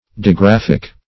Digraphic \Di*graph"ic\, a.